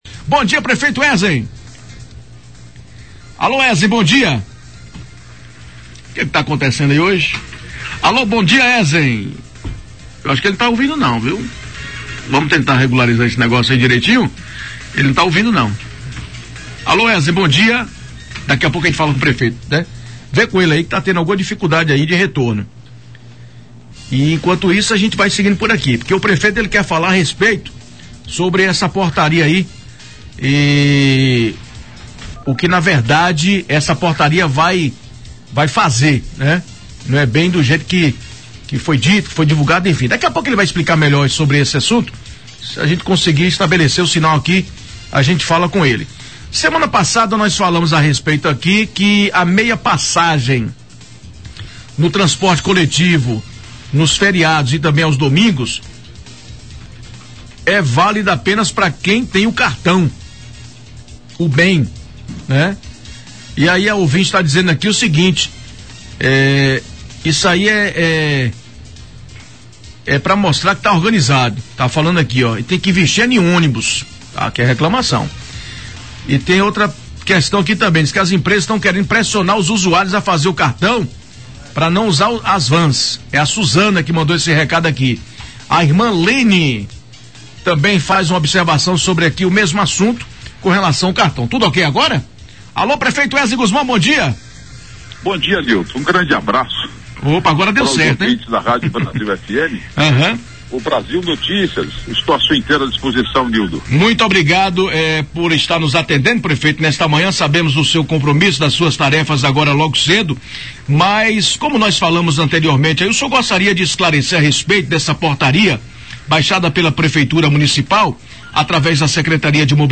O prefeito Herzem Gusmão Pereira utilizou o Brasil Notícias, na Rádio Brasil FM, para explicar o imbróglio em torno da Portaria Nº 01/2017, que proíbe o embarque e desembarque de ageiros do Transporte Coletivo Intermunicipal em Vitória da Conquista.
Durante a reportagem Herzem disse que houve um erro na redação e explicou que a medida só é válida para o trecho do Gancho, localizado entre as avenidas Bartolomeu de Gusmão e Juracy Magalhães. Ouça a explicação do chefe do Executivo Conquistense.